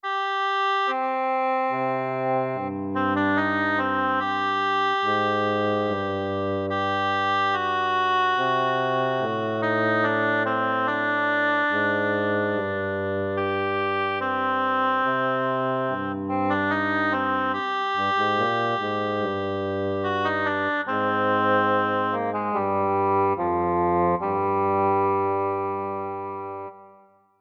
ambiente
melodía
sintonía
Sonidos: Música